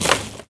R - Foley 156.wav